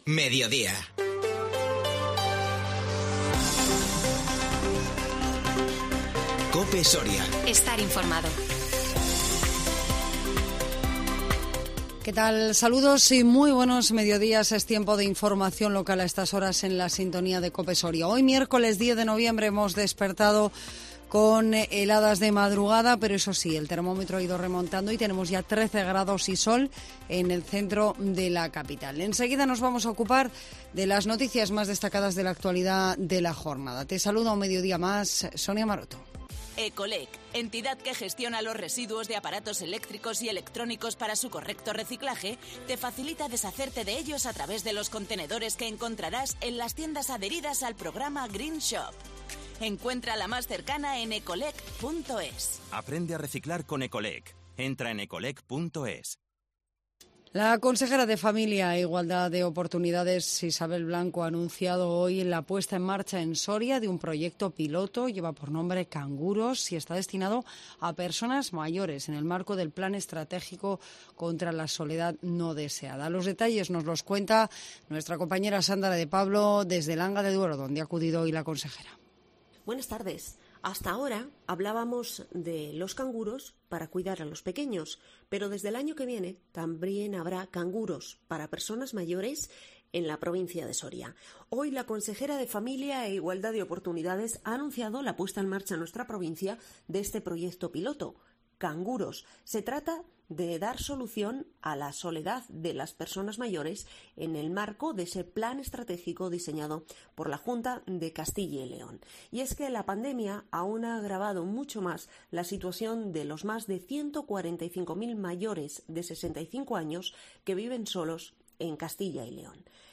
INFORMATIVO MEDIODÍA 10 NOVIEMBRE 2021